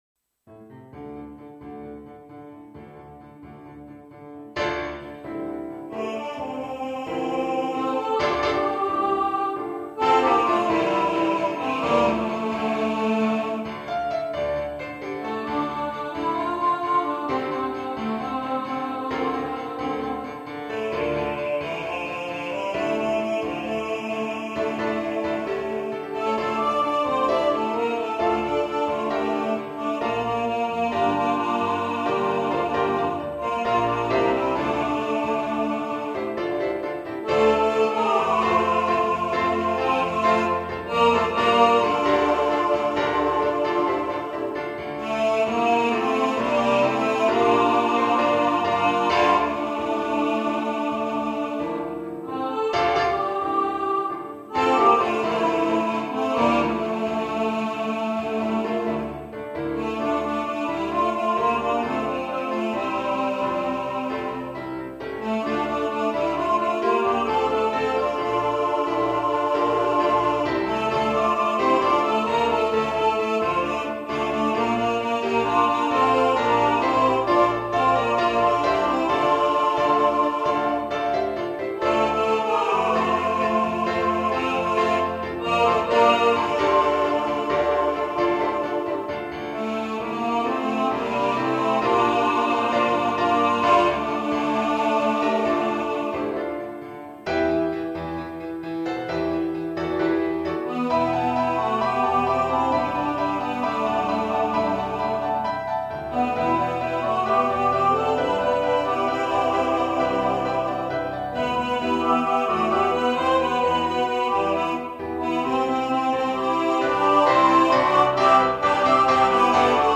Ghost Riders In The Sky All Voices | Ipswich Hospital Community Choir
Ghost-Riders-In-The-Sky-All-Voices.mp3